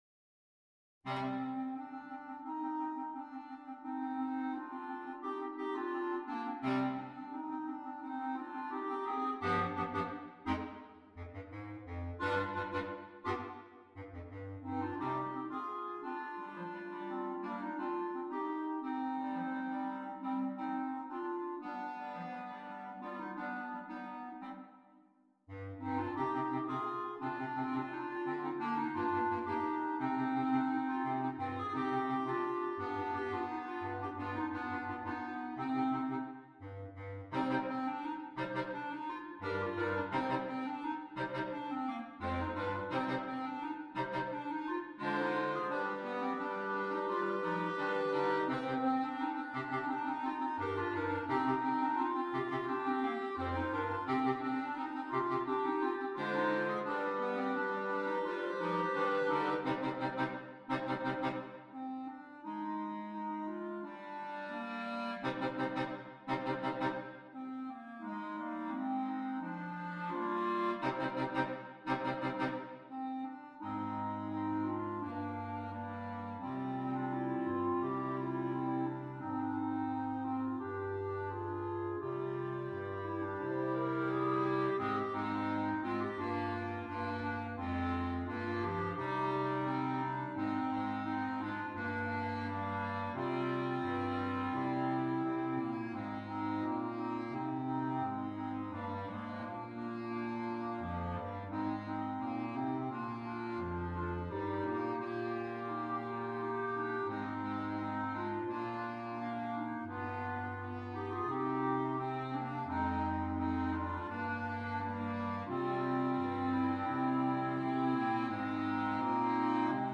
4 Clarinets, Bass Clarinet